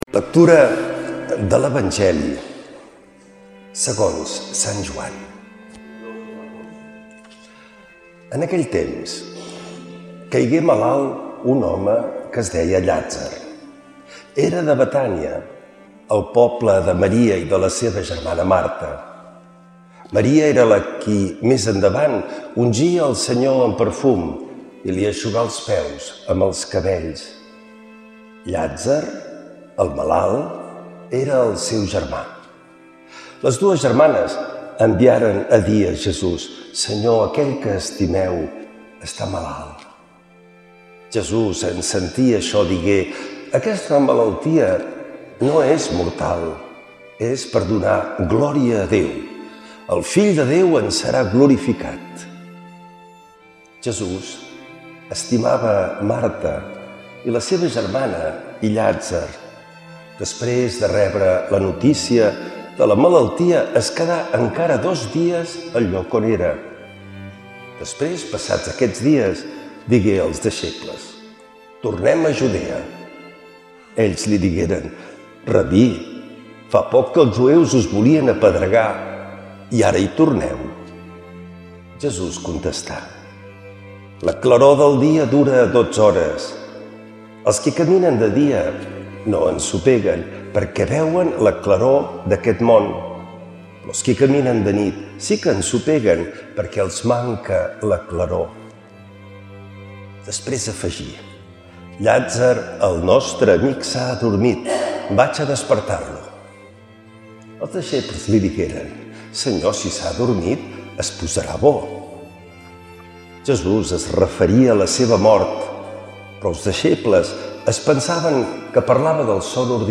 L’Evangeli i el comentari de diumenge 22 de març del 2026.
Lectura de l’Evangeli segons Sant Joan.